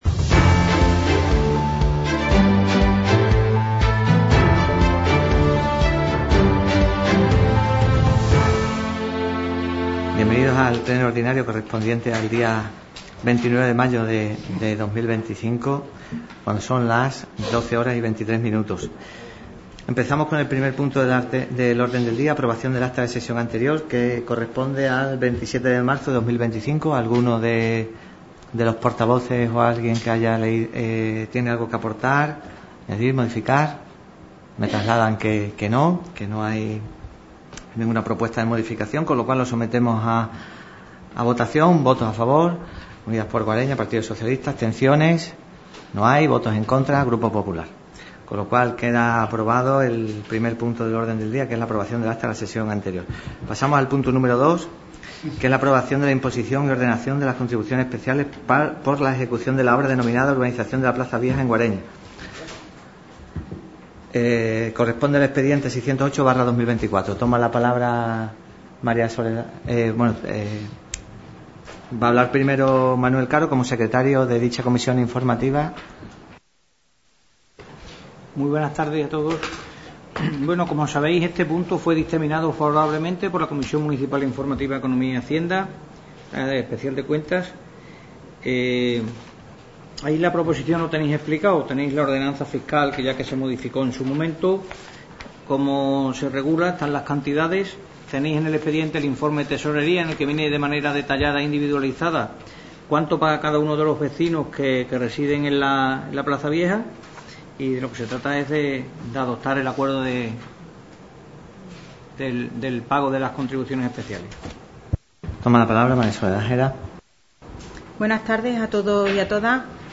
Sesión ORDINARIA de Pleno, 30 de Mayo de 2025 - radio Guareña
Sesión celebrada en el Ayuntamiento de Guareña.